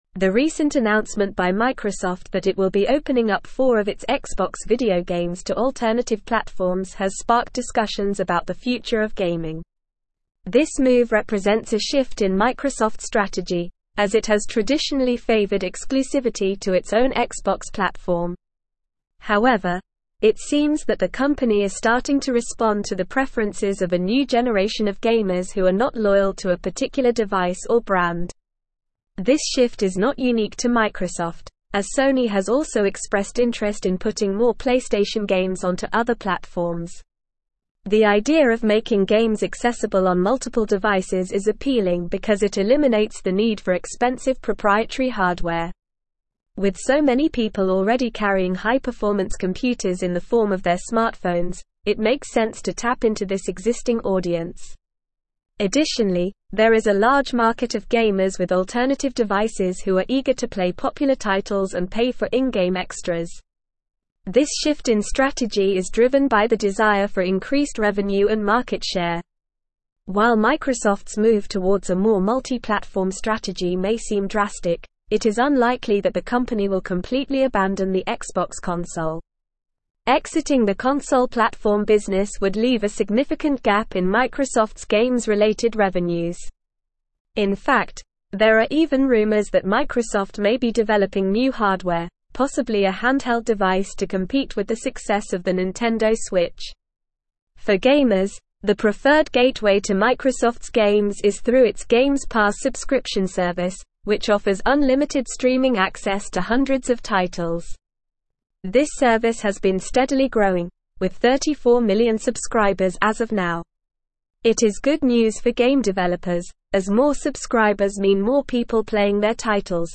Normal
English-Newsroom-Advanced-NORMAL-Reading-Microsofts-Xbox-Expands-Gaming-Strategy-to-Multiple-Platforms.mp3